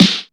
Snare
Original creative-commons licensed sounds for DJ's and music producers, recorded with high quality studio microphones.
good-steel-snare-drum-sound-f-sharp-key-113-tsH.wav